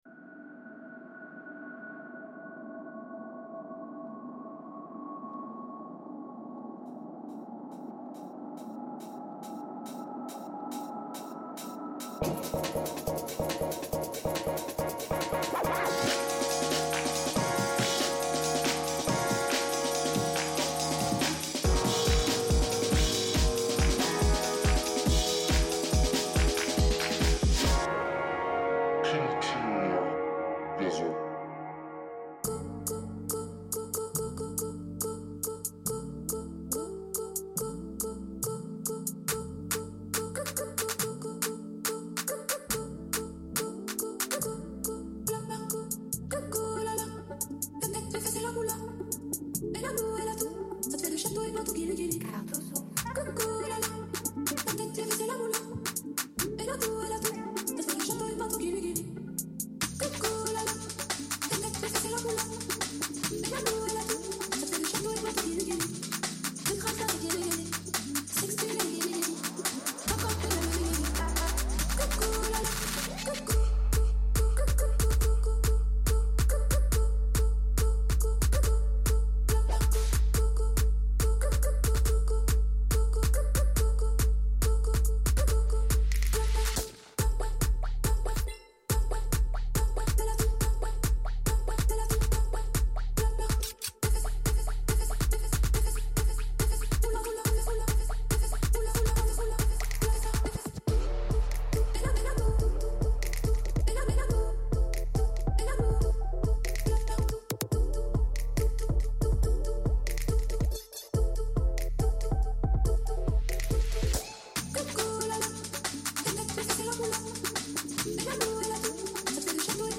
set disco/pop qui ravira baskets et planchers